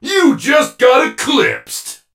surge_lightyear_kill_vo_07.ogg